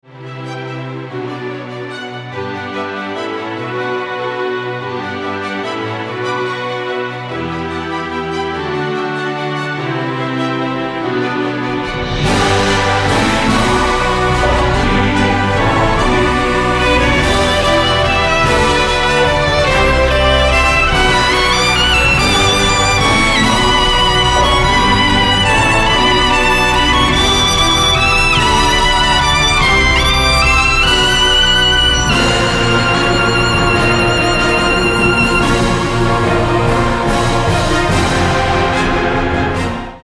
ソロヴァイオリン